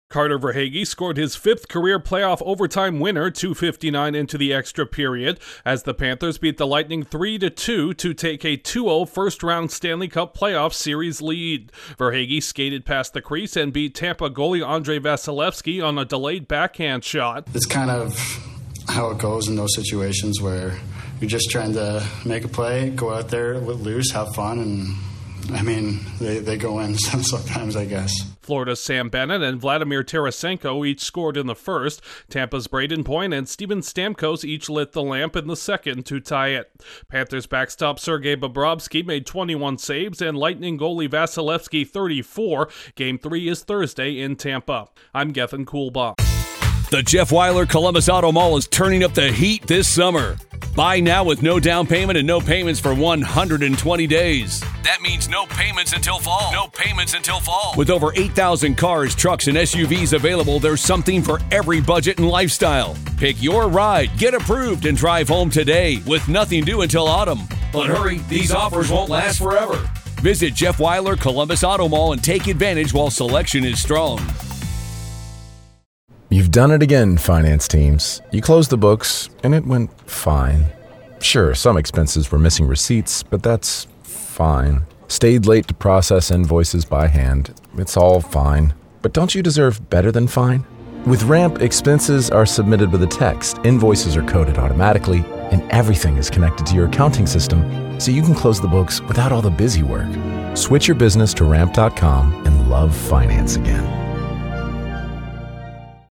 The Panthers prevail after wasting a 2-0 lead. Correspondent